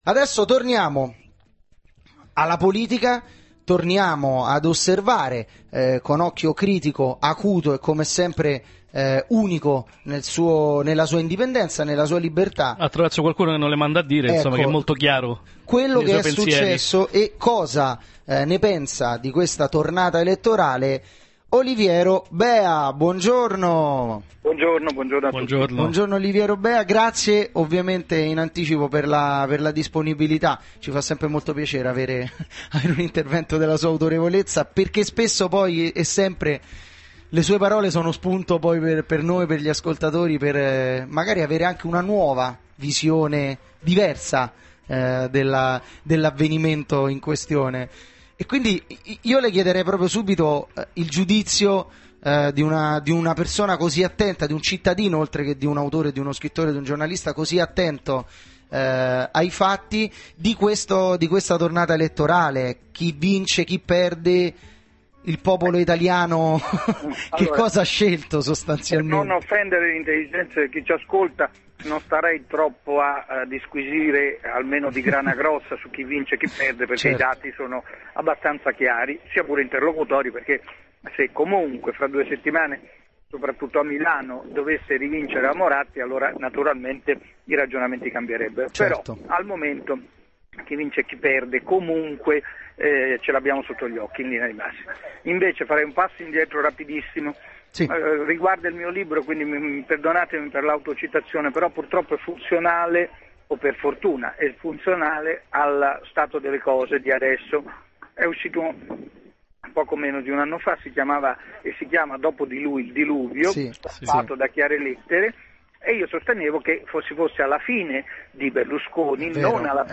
Intervento telefonico Oliviero Beha